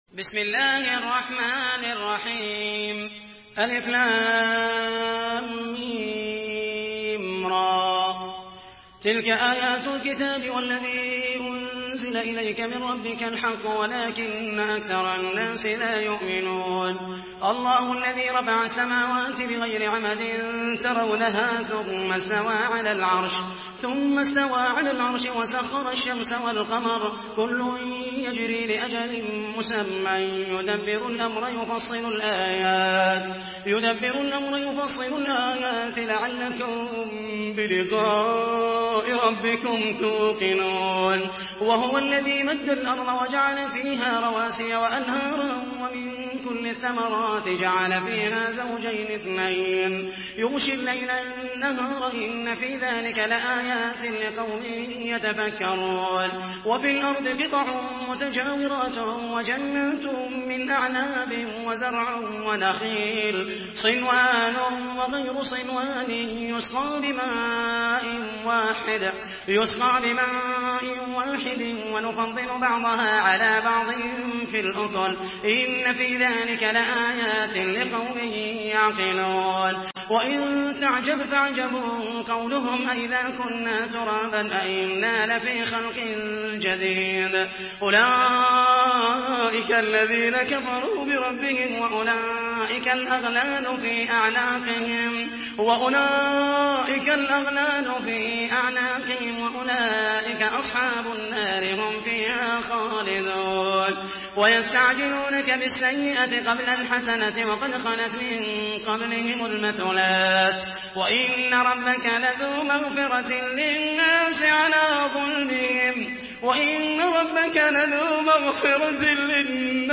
Surah Ar-Rad MP3 by Muhammed al Mohaisany in Hafs An Asim narration.
Murattal Hafs An Asim